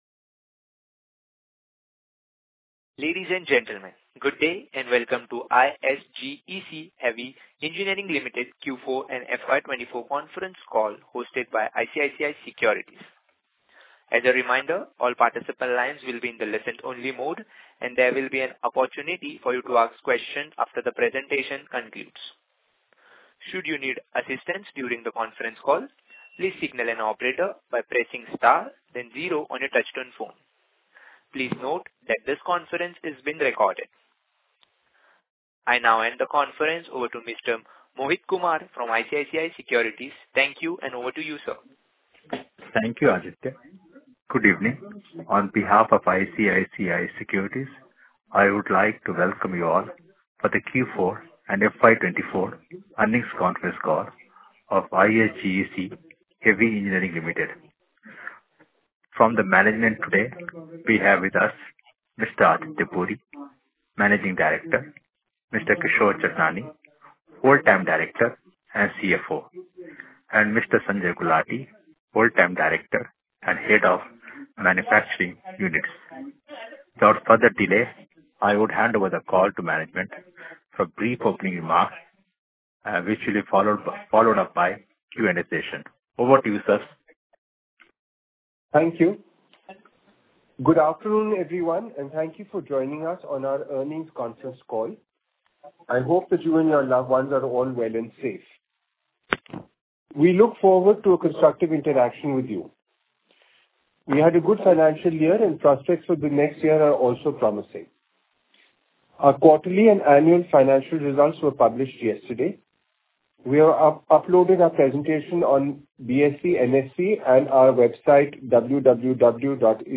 ISGEC Heavy Engineering Ltd | Heavy Engineering in India | Conference Call for the Financial Performance
IsgecQ4FY24EarningsCallAudio.mp3